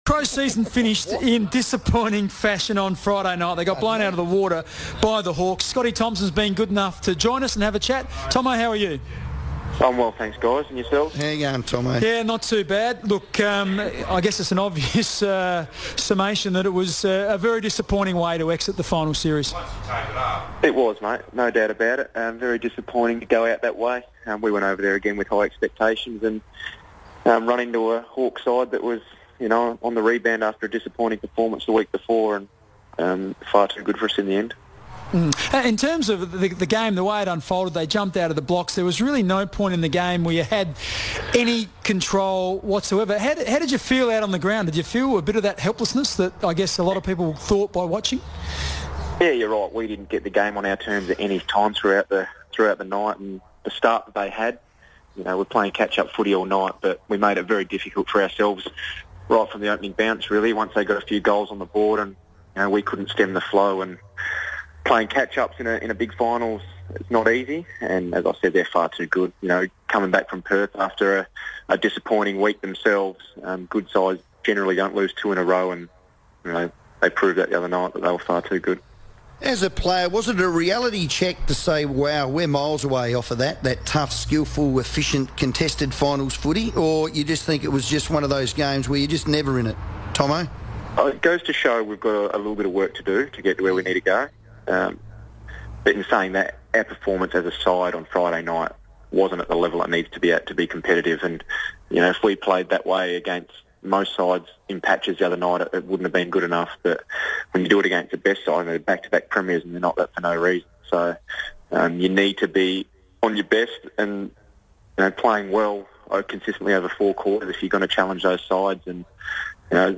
Midfielder Scott Thompson joined the FIVEaa Sports Show to reflect on Adelaide's 2015 campaign.